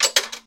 barn_coin_drop.ogg